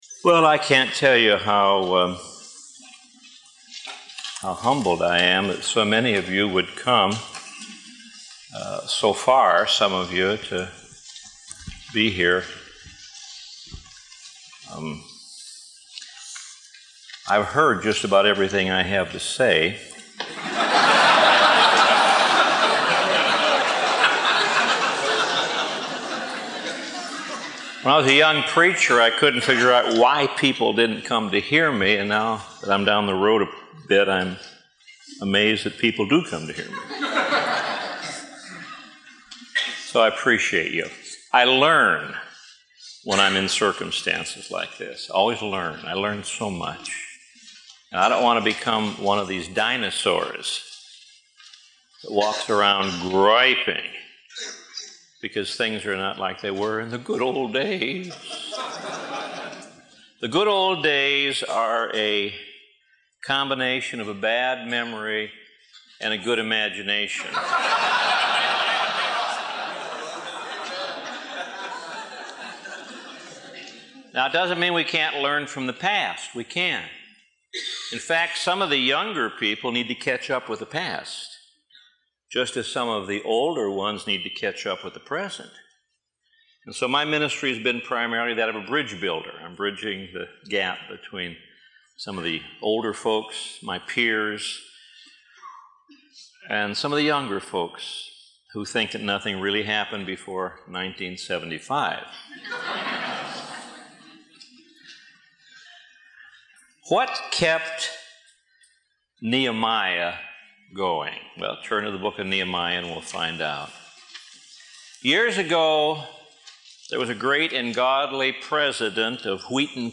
Pastor Warren Wiersbe teaches session two at the “Pastor to Pastor” conference held at Calvary Chapel of San Bernardino.